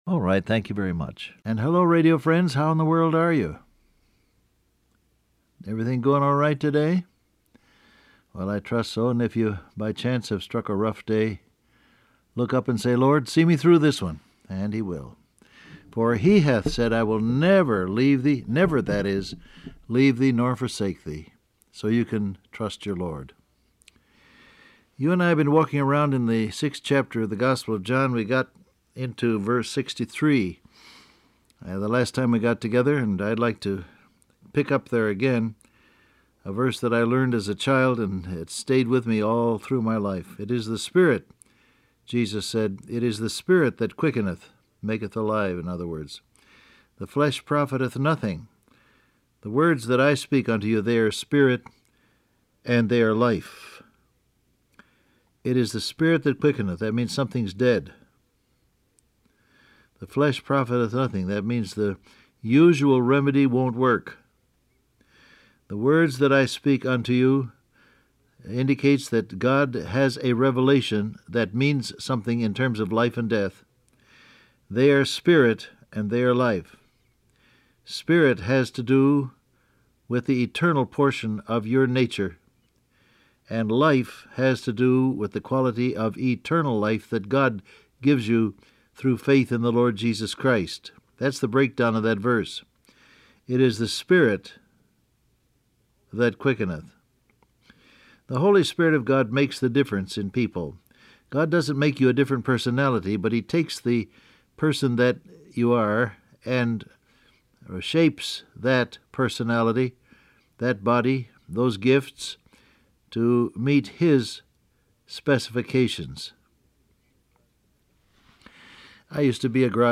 Download Audio Print Broadcast #6857 Scripture: John 6:63-69 Topics: Word of God , Spirit And Life , The Flesh Transcript Facebook Twitter WhatsApp And hello, radio friends, how in the world are you?